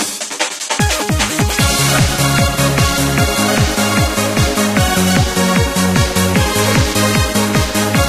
突然ブチッと切れて終わっています。